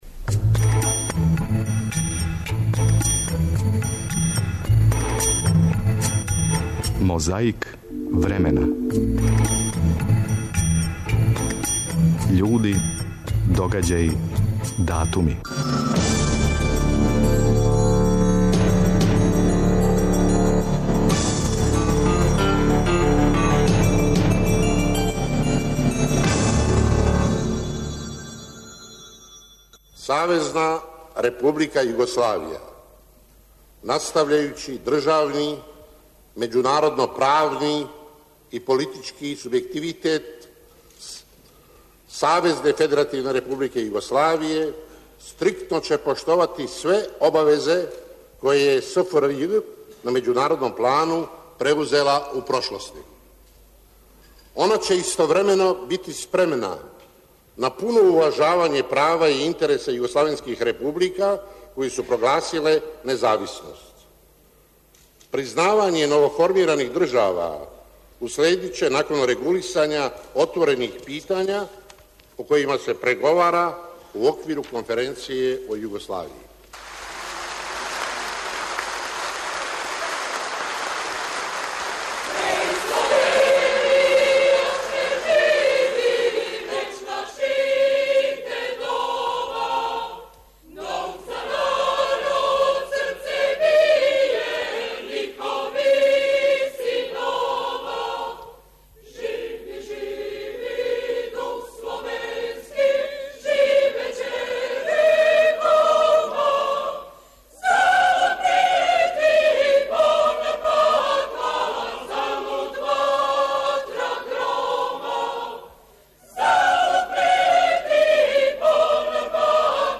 Тим поводом чућемо изјаву југословенског делегата Станоја Симића приликом потписивања повеље УН, што је било два месеца после оснивачке конференције. 21. априла 1999. у НАТО агресији на Југославију, бомбардован је пословни центар Ушће у Београду и избегличко насеље у Сурдулици, а 23. априла бомбардована је зграда РТС-а у Абердаревој улици.